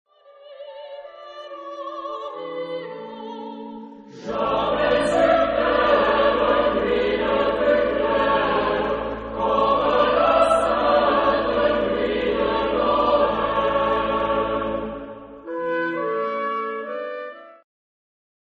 Genre-Stil-Form: Volkslied ; Liedsatz ; geistlich
Charakter des Stückes: lebhaft ; dreizeitig
Solisten: Soprano (1)  (1 Solist(en))
Tonart(en): A-Dur